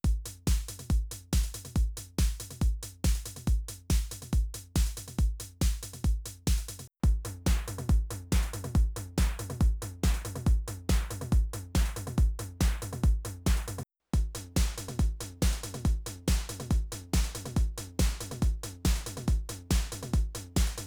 On all of the below, there are four channels from the TR-8: kick, snare/clap, toms/rim, hats.
The FX is EQ’d, with a slight low increase, slight cut at 125, and highs of 8k+ boosted.
Here’s a dry loop, then a loop of the Joyo Baatsin T. OD and T808: